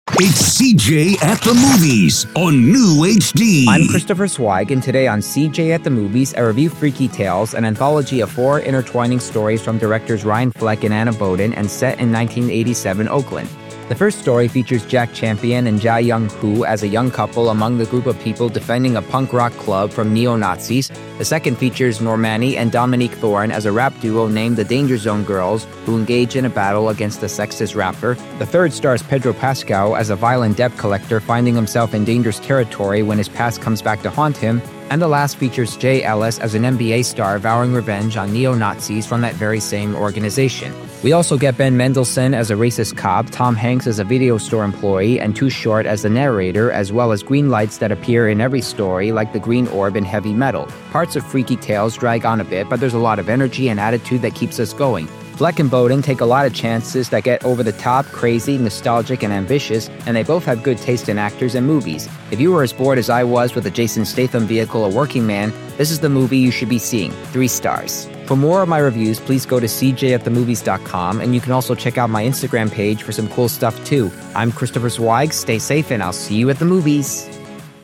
I’m part of a radio station known as NEWHD Radio, which not only hires people on the Autism spectrum, but also has me doing podcast movie reviews for them.